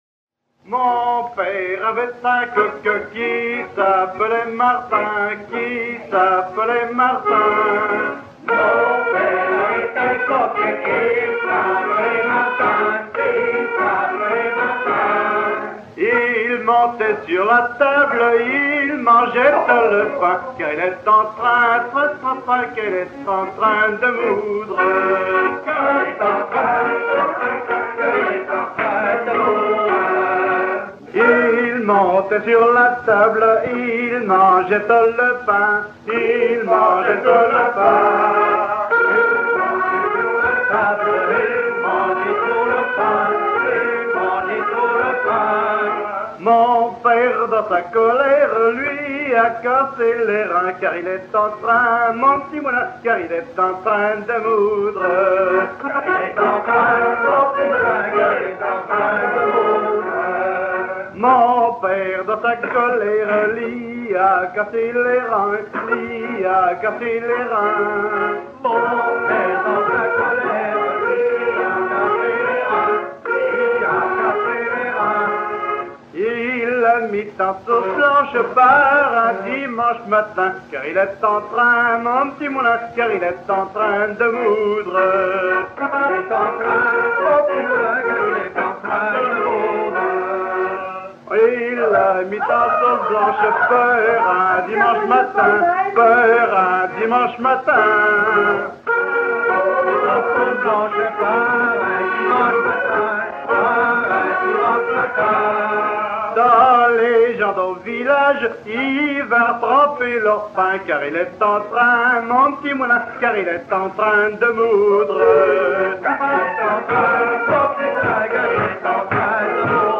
Genre laisse
Pièce musicale éditée